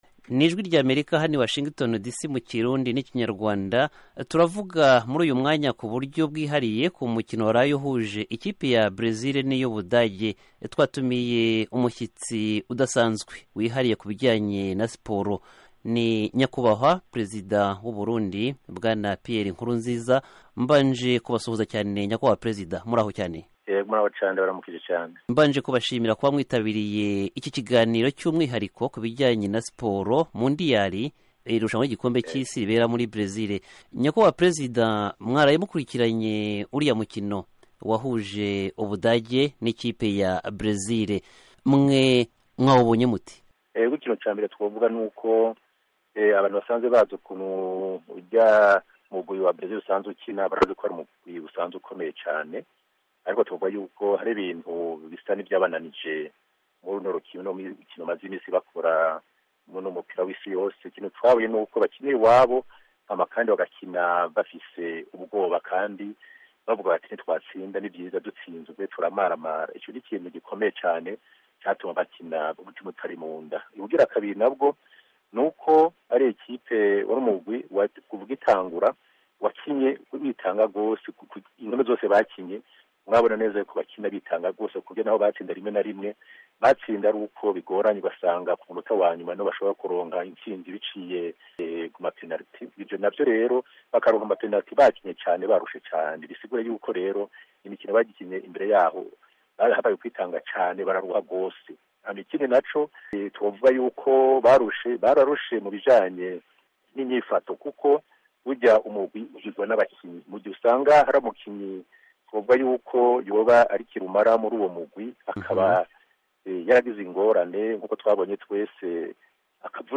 Nyamara, kuri iyi nshuro, Ijwi ry'Amerika ryasekewe n'ayo mahirwe Perezida w'u Burundi Petero Nkurunziza yemera kuvuga, kuri telefoni, ku mukino wahuje Bresil n'Ubudage.